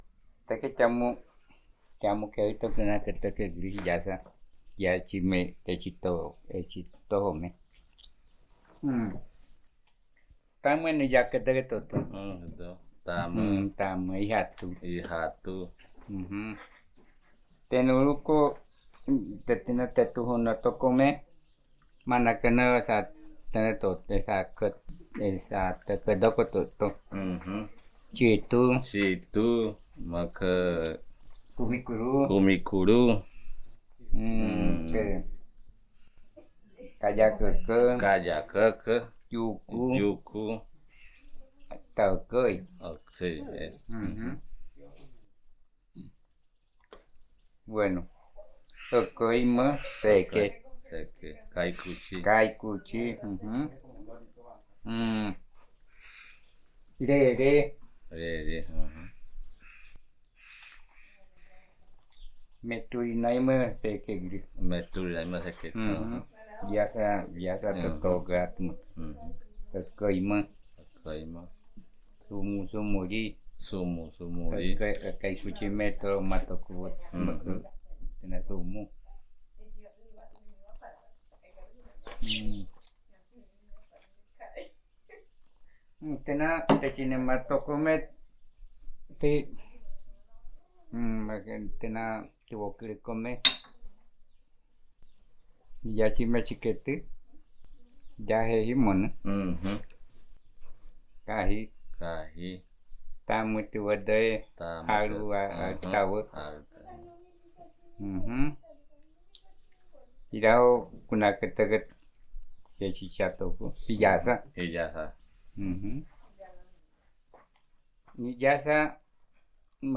Conversación sobre payés
Puerto Nare, Guaviare